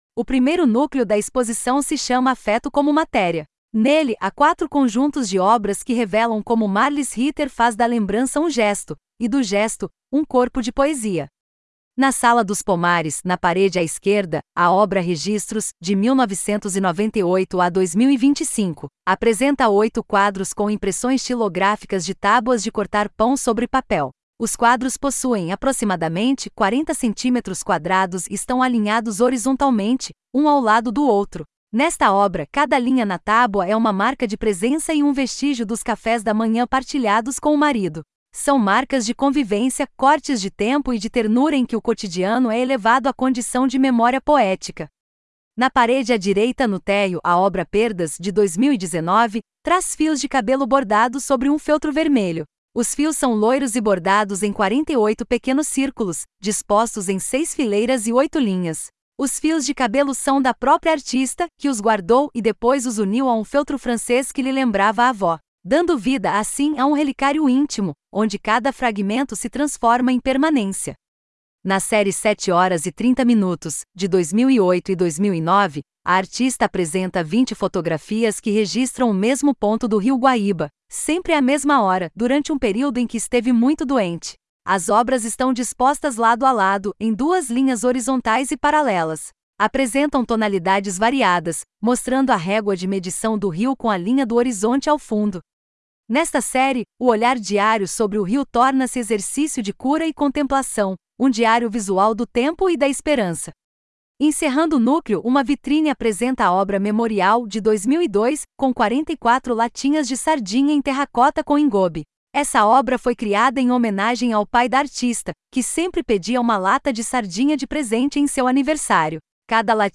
Audiodescrição do Núcleo 1 | Afeto como Matéria